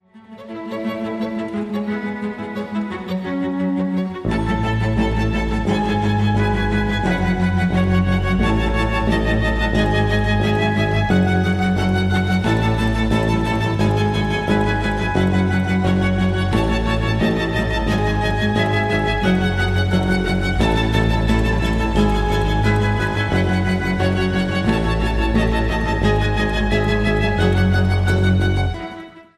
Category: Classical music ringtones